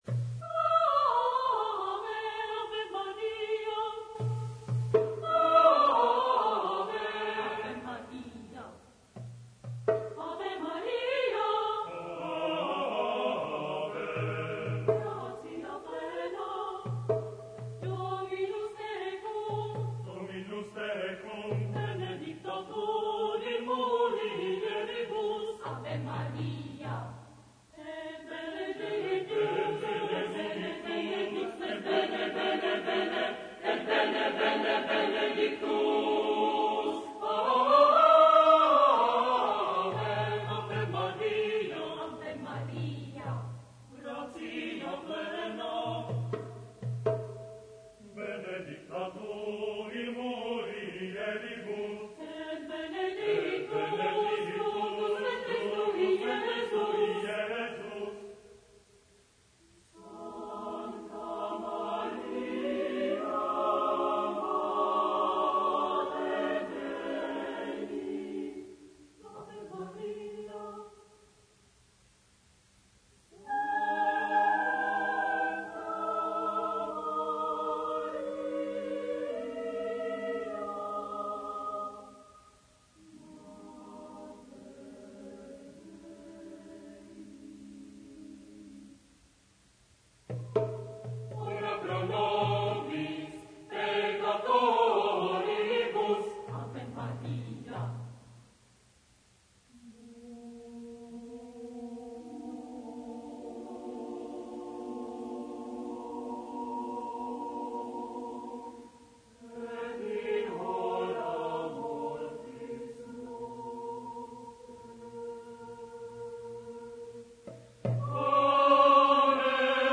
SATB (4 voices mixed) ; Full score.
Sacred. Motet.
Consultable under : 20ème Sacré Acappella